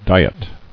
[di·et]